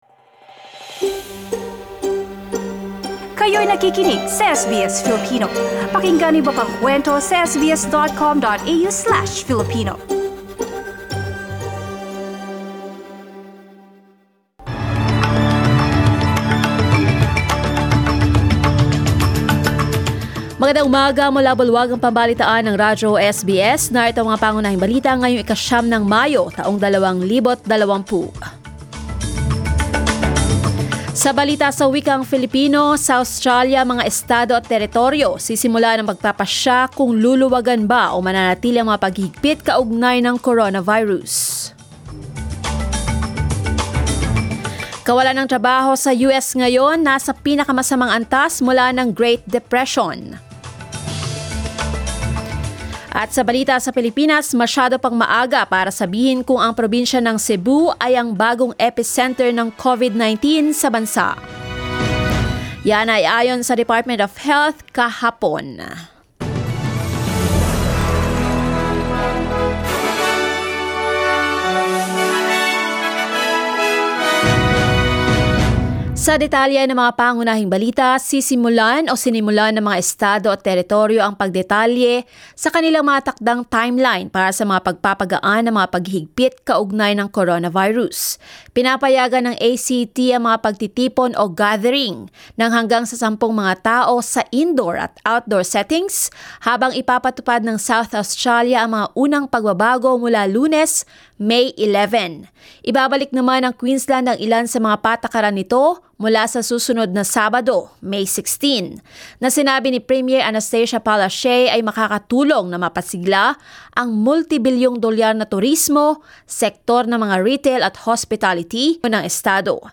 SBS News in Filipino, Saturday 09 May